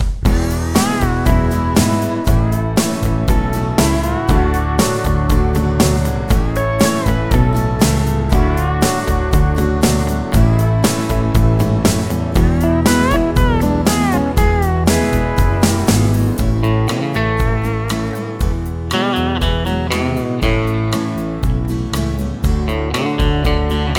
no Backing Vocals Country (Male) 3:44 Buy £1.50